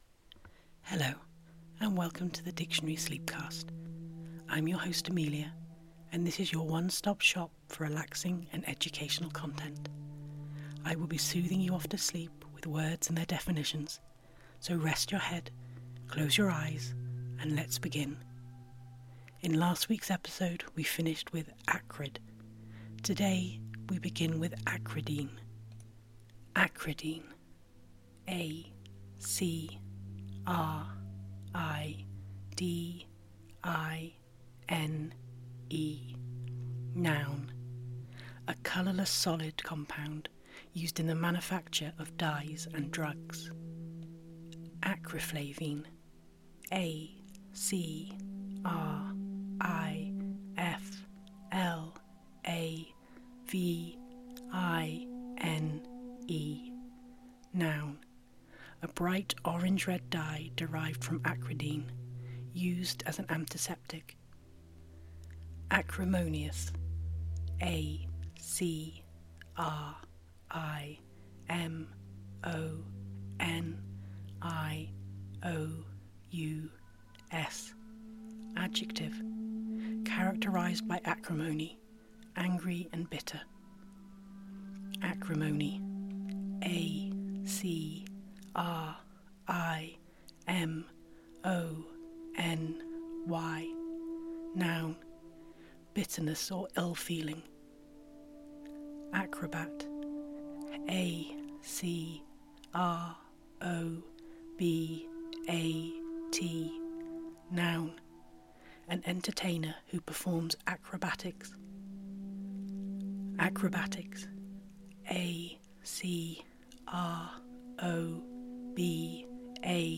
Relax and drift off to sleep to the sound of someone reading words and their definitions to you.
Can't sleep, or just need to listen to something restful, this is the podcast for you.